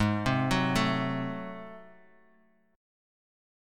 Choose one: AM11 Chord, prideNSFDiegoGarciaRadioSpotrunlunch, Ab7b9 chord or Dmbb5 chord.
Ab7b9 chord